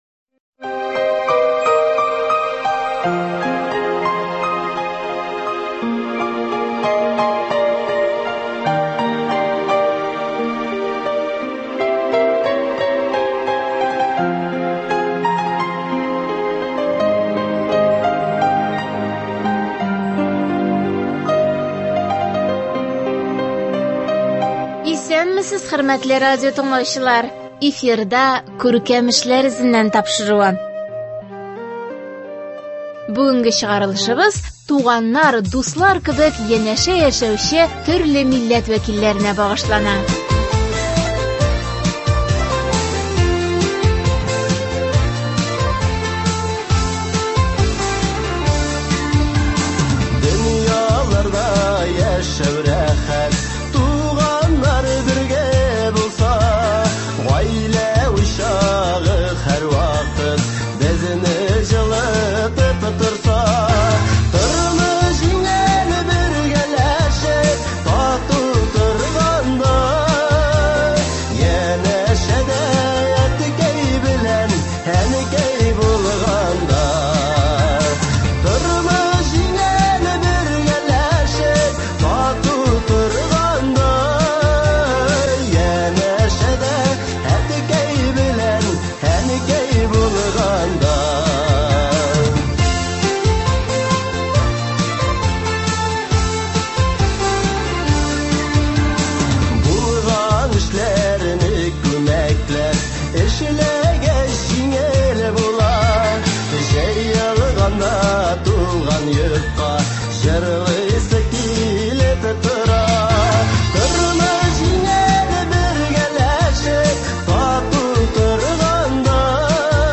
Тапшыру Балтач районының Чепья авылыннан әзерләнде. Биредә урнашкан мәктәптә төрле милләт балалары укый, алар туган телләрен, мәдәниятләрен өйрәнә, шул рәвешле дуслык традицияләре киләчәктә дә дәвам итәр дигән өмет бар!